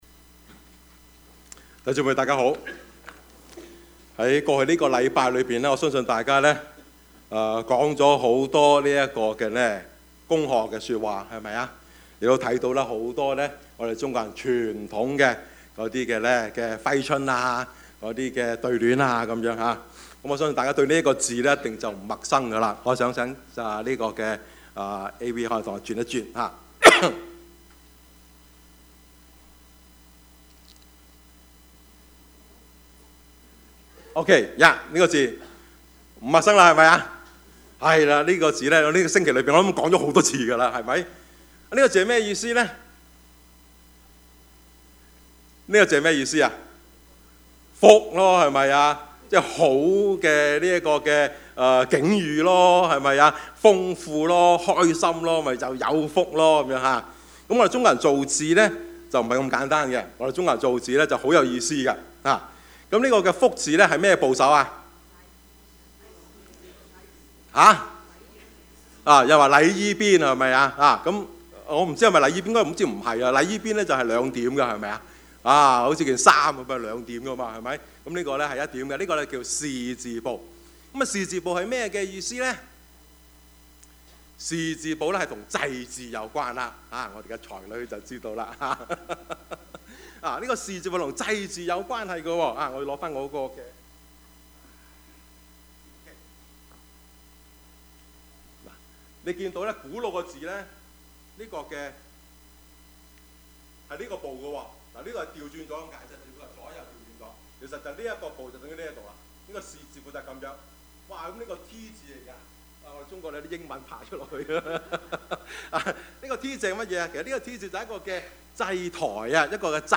Service Type: 主日崇拜
Topics: 主日證道 « 吃喝玩樂，然後…..